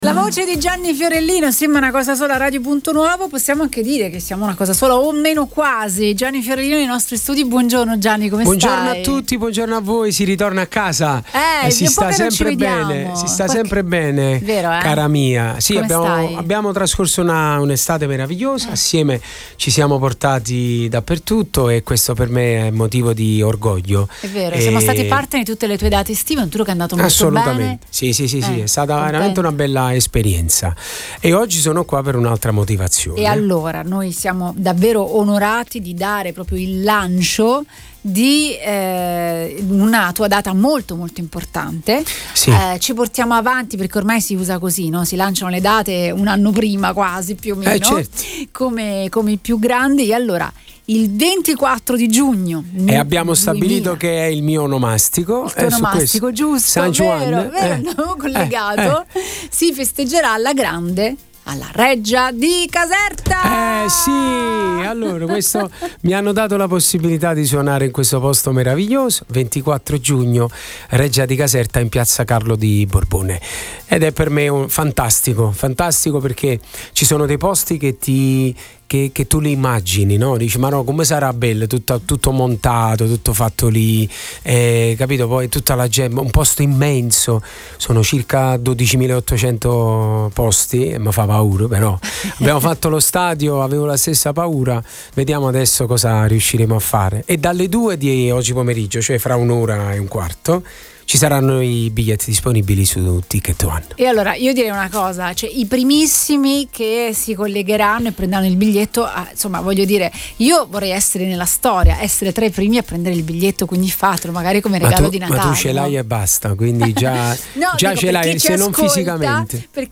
Dagli studi di Napoli di Radio Punto Nuovo Gianni Fiorellino ha annunciato l’evento più importante della sua prossima stagione live: un concerto monumentale, fissato per il 24 giugno, giorno del suo onomastico, nella splendida cornice della Reggia di Caserta, in Piazza Carlo di Borbone.